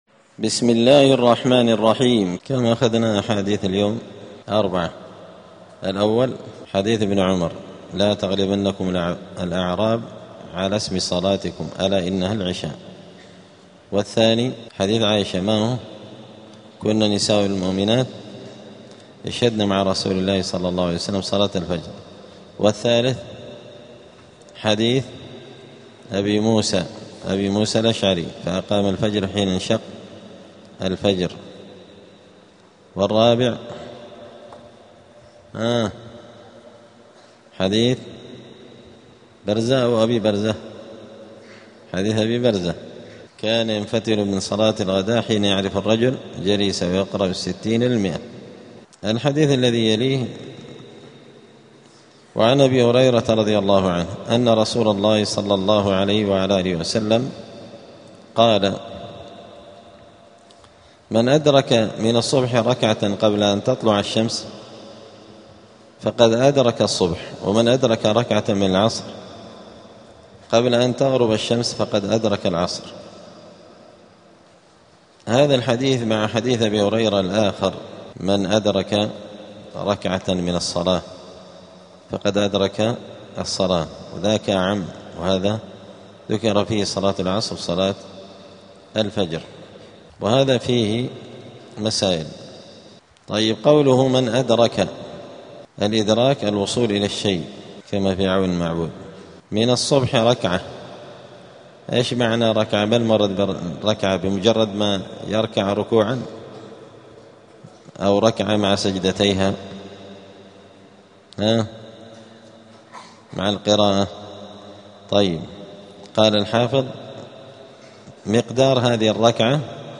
دار الحديث السلفية بمسجد الفرقان قشن المهرة اليمن
*الدرس الأربعون بعد المائة [140] {إدراك ركعة ممن الصلاة قضاء أو أداء}*